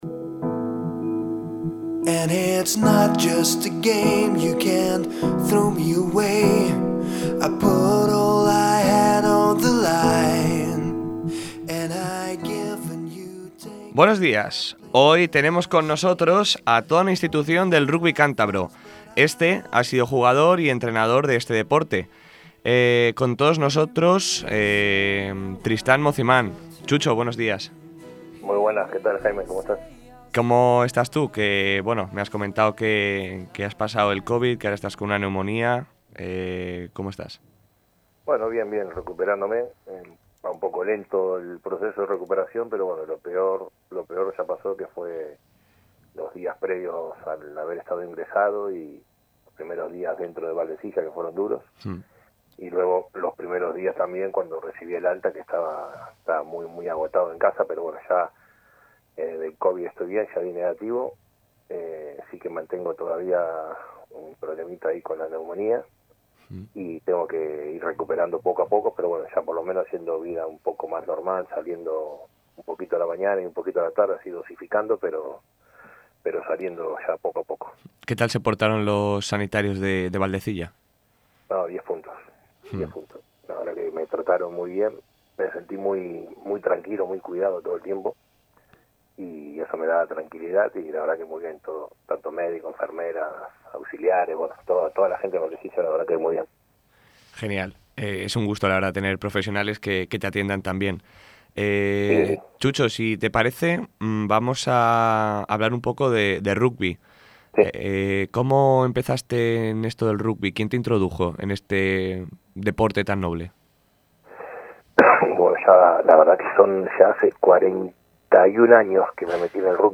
Audio_de_la_entrevis_2.mp3